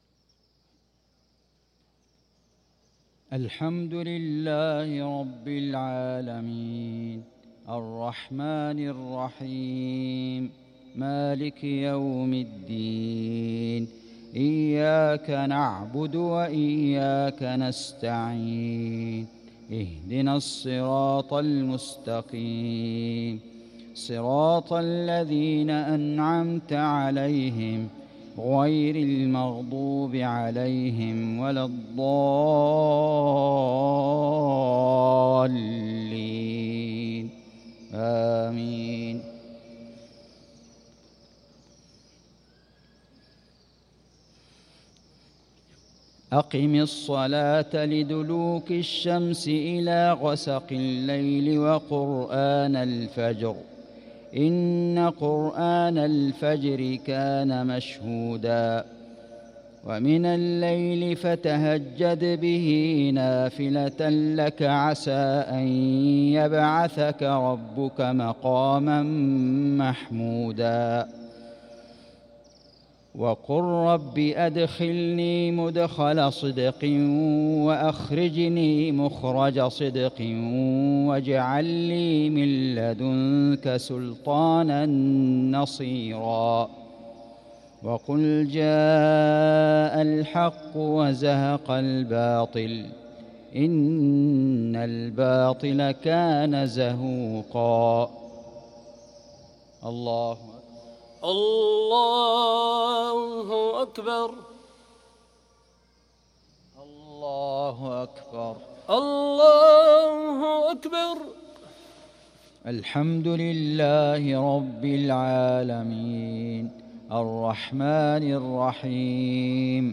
صلاة المغرب للقارئ فيصل غزاوي 17 شعبان 1445 هـ
تِلَاوَات الْحَرَمَيْن .